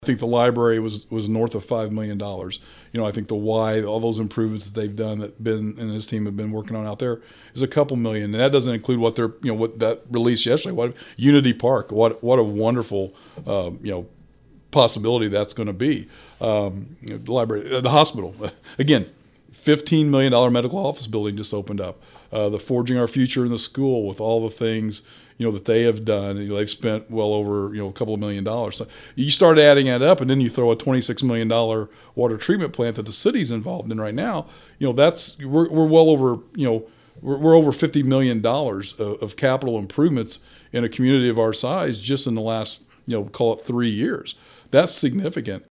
Earlier this week, the Family YMCA of Fayette County announced on social media an expansion of their campus into an additional 16 acres between the Y and Kaskaskia College that would be known as Unity Park.  Speaking on our weekly podcast “Talking about Vandalia,” Mayor Doug Knebel says there’s been a lot of investment in the community over just the past few years.